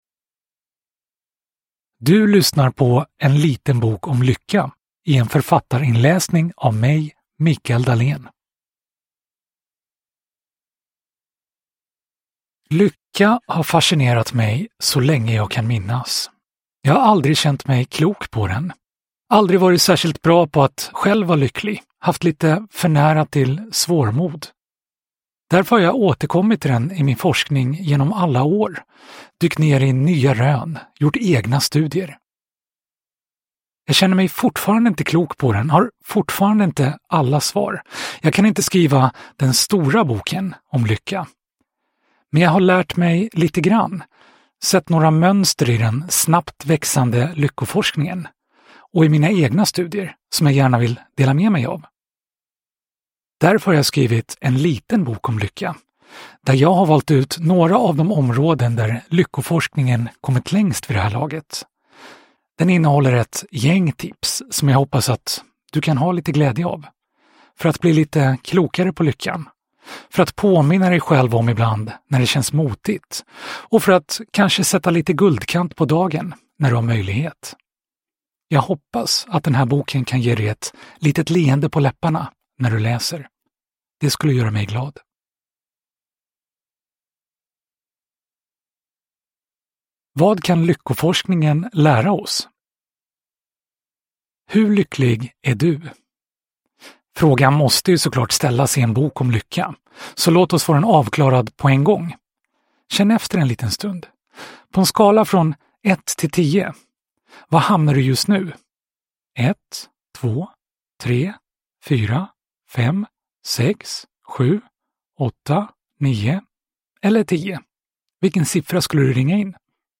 En liten bok om lycka – Ljudbok – Laddas ner